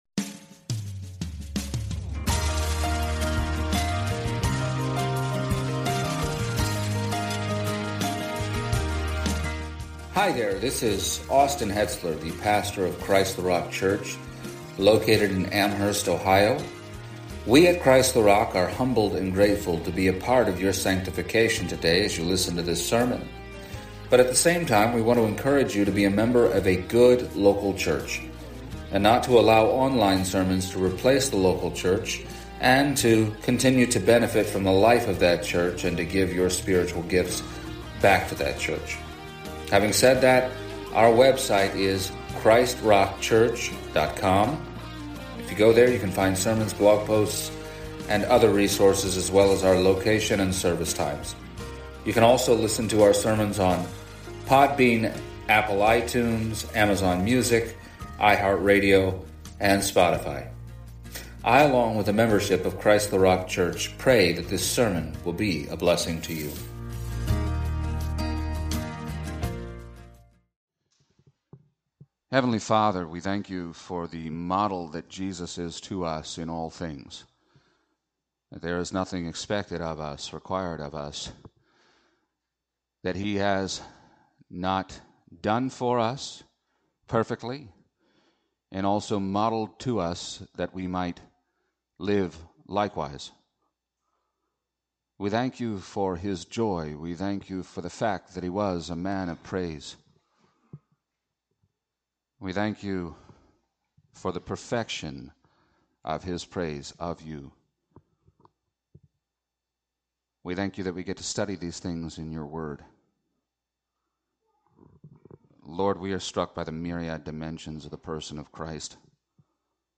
Passage: Hebrews 2:11-12 Service Type: Sunday Morning